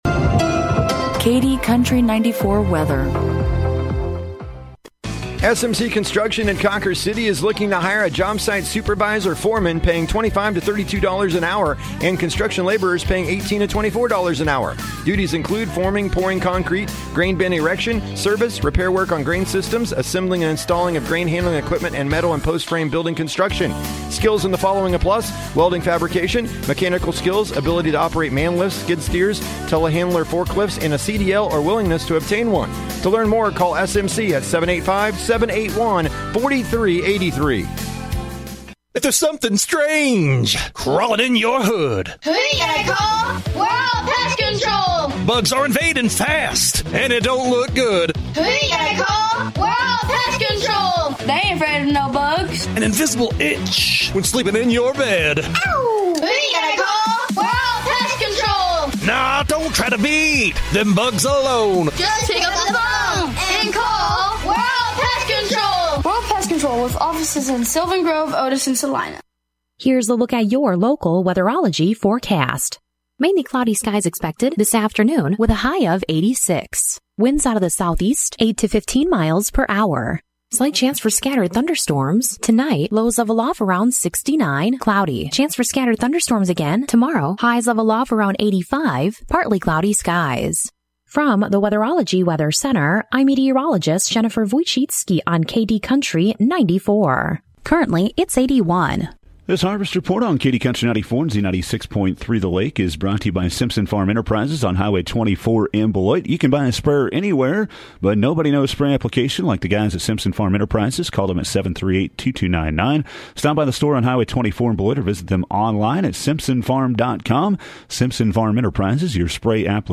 KD Country 94 Local News, Weather & Sports – 6/22/2023